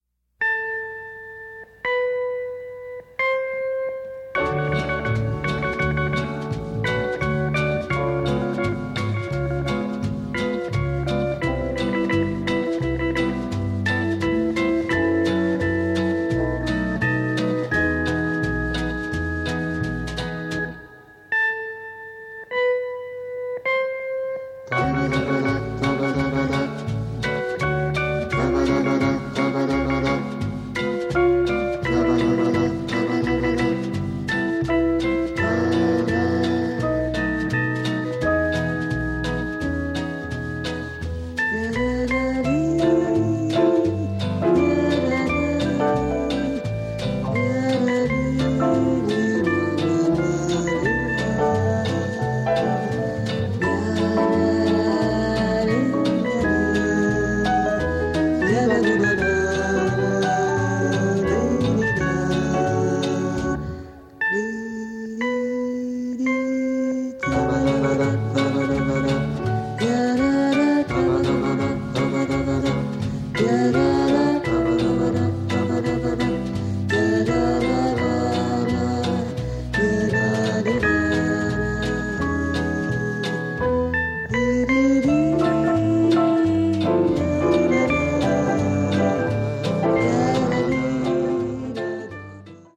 the melodies are stunningly beautiful.